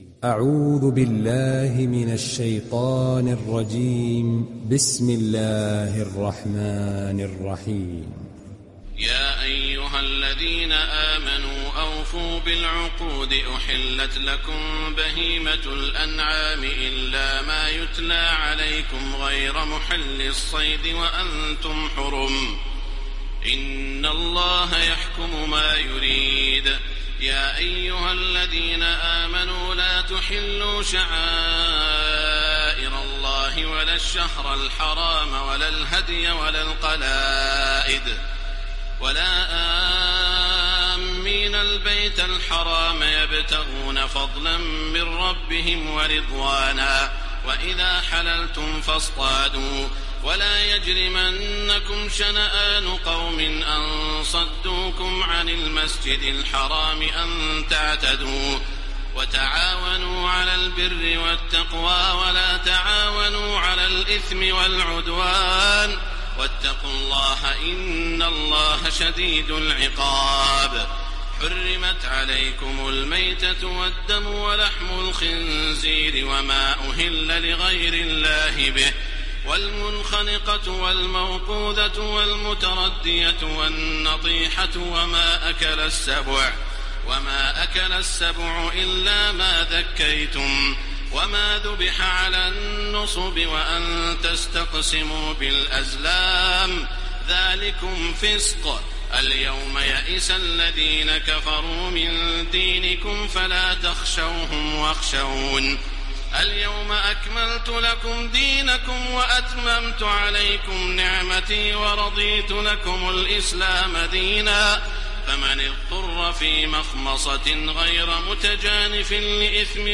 دانلود سوره المائده تراويح الحرم المكي 1430